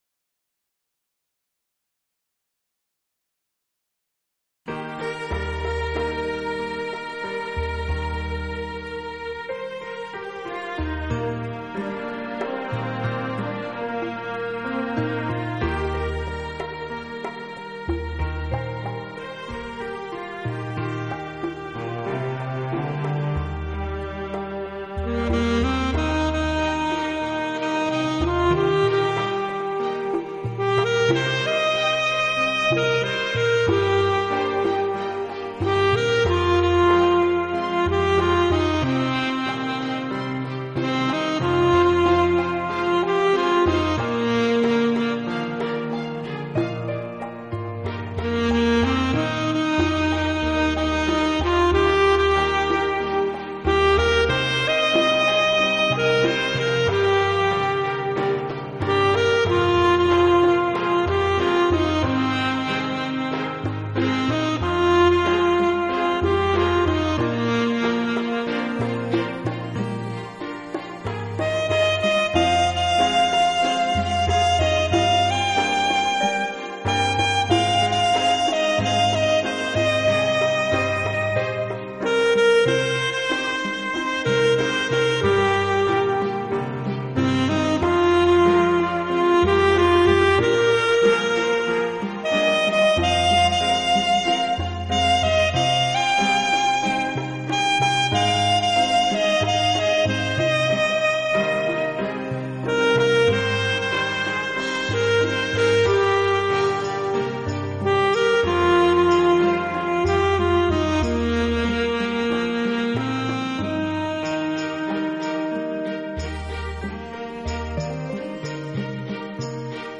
【纯音萨克斯】
我的爱因你而存在缠绵的萨克斯乐曲，演绎浪漫的情人物语，
这丝丝情意像恋人般彼此依偎，轻轻地，静静地直到永远！